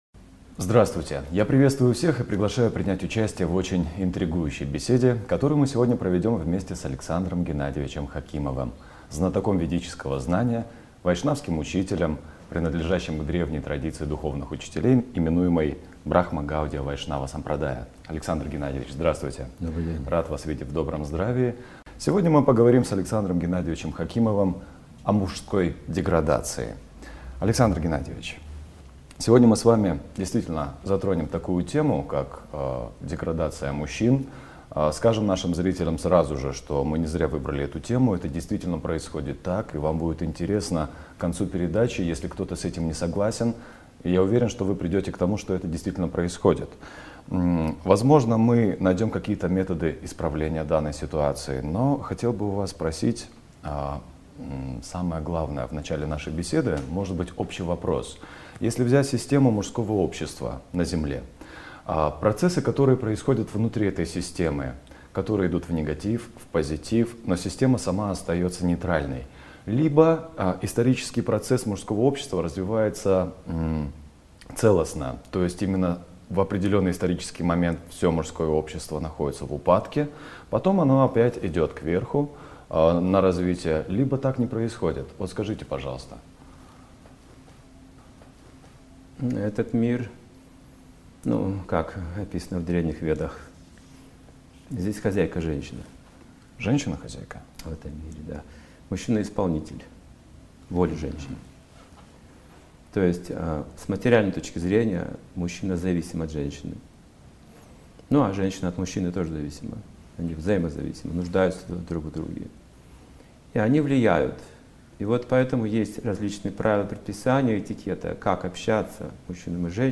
2020.02, Алматы, Интервью в программе "Главные темы в кино", Фильм первый - Мужская деградация